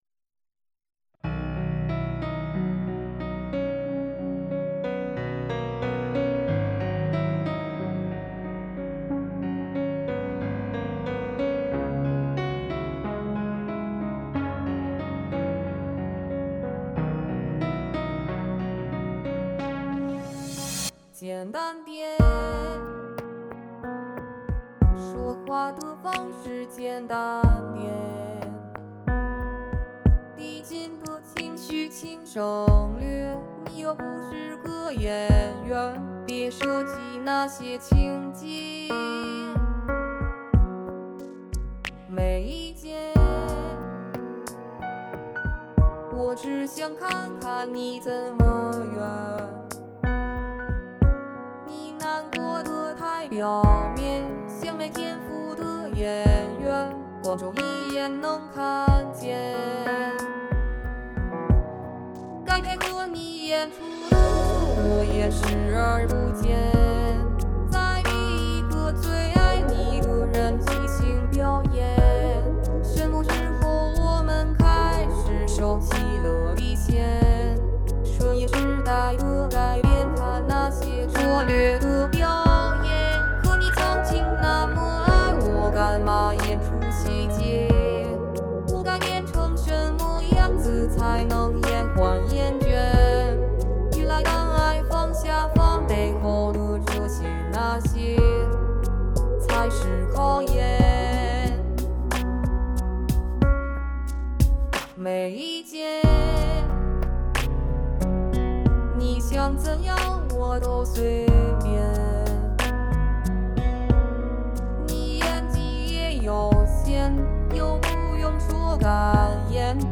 最近逛bilibili挺多，对Vocaloid挺感兴趣，使用虚拟歌手制作翻唱以及原创歌曲相当有趣，于是自己打算玩玩。